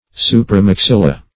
Search Result for " supramaxilla" : The Collaborative International Dictionary of English v.0.48: Supramaxilla \Su`pra*max"il*la\, n.; pl.